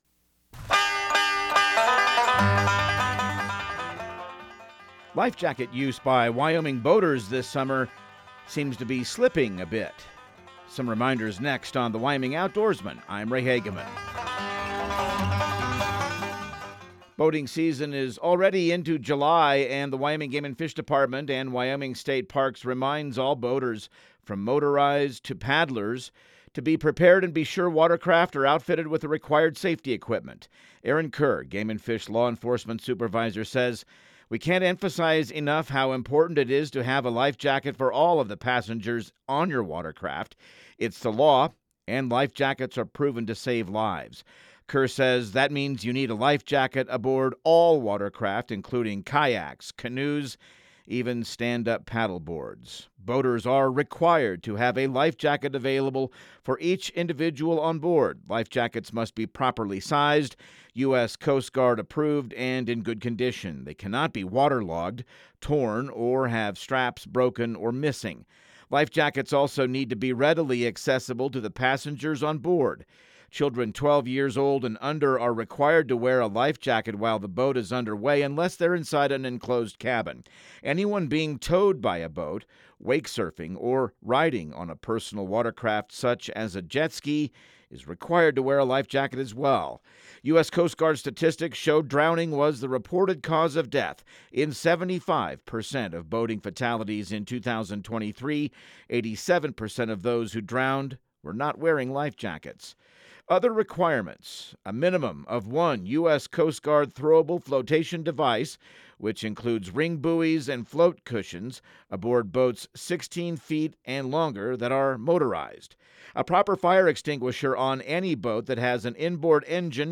Radio news | Week of July 14